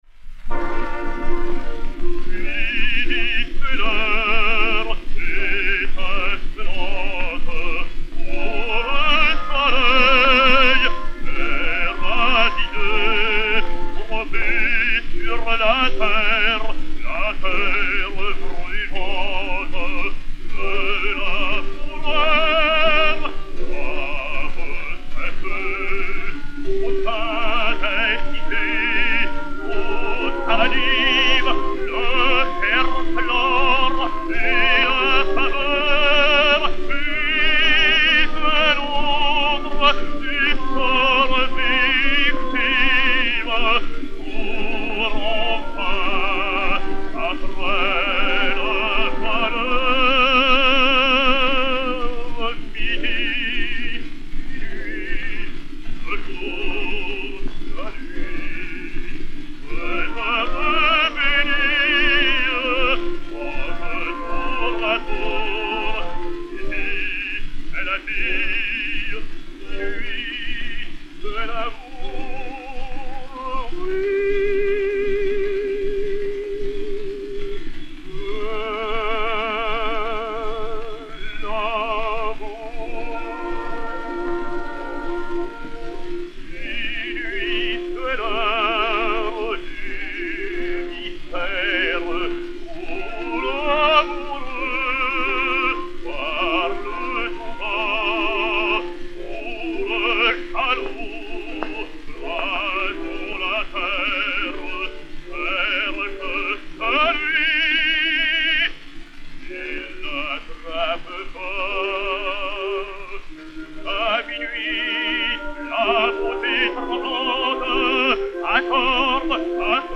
Daniel Vigneau (le Docteur Mirouet) et Orchestre